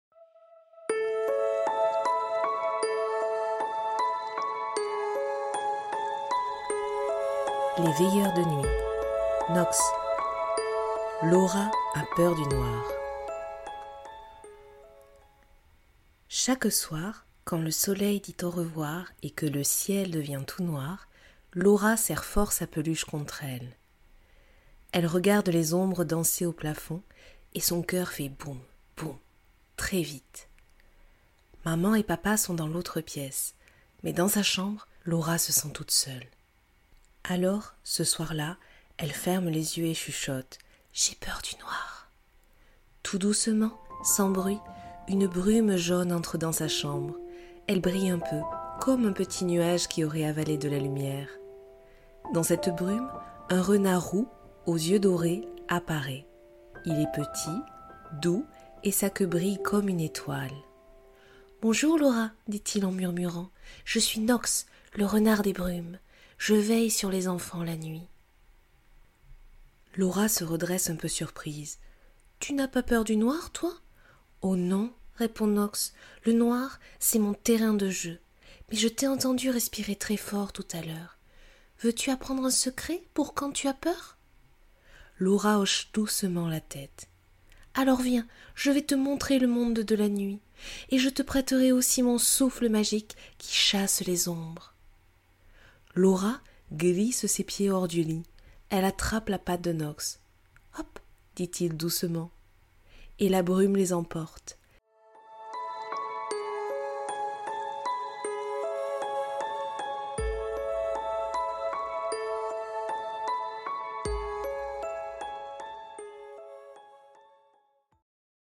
Audiobook “Laura a peur du noir” : la version audio à écouter avant de dormir pour se laisser bercer par l’histoire.
Extrait-Audio-Laura-a-peur-du-noir-conte-Nox.mp3